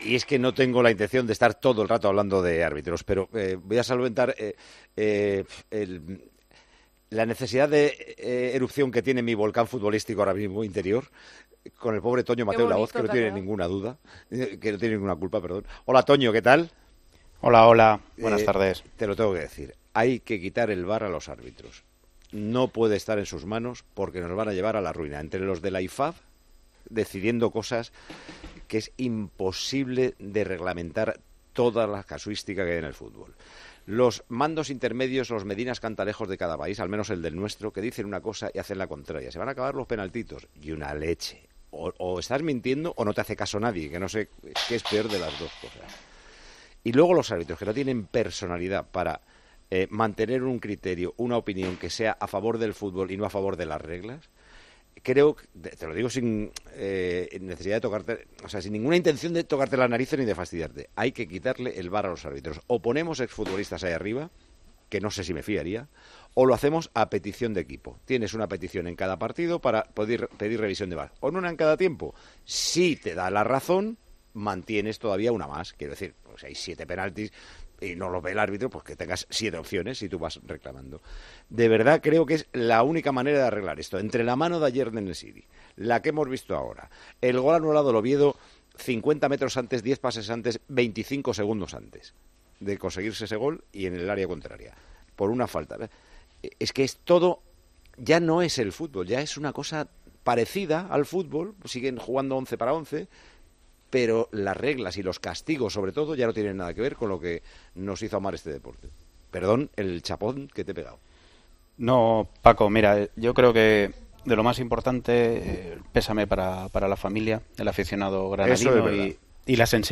El discurso completo de Mateu Lahoz en Tiempo de Juego